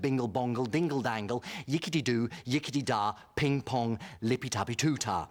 Bingle Bongle Dingle Dangle - Ringtone.wav